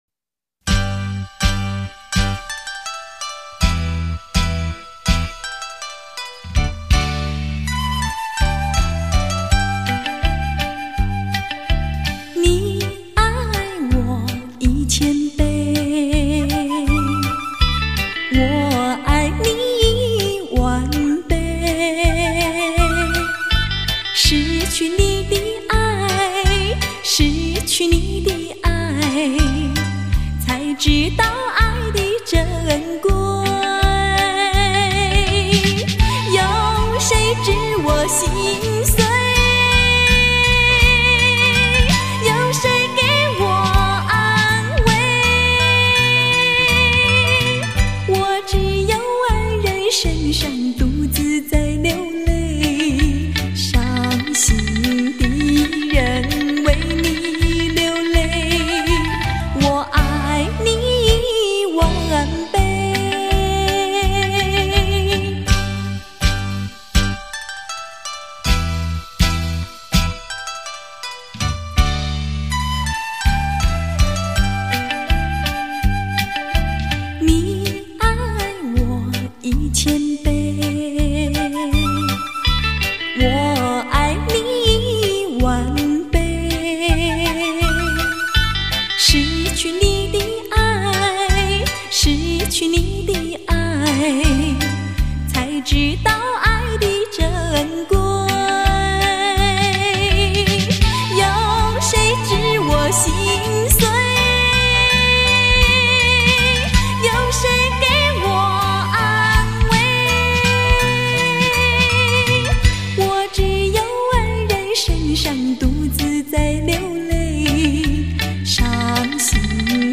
她的中音抒情，很能表达出歌曲中的缕缕情意，歌声细致深透，荡气回肠。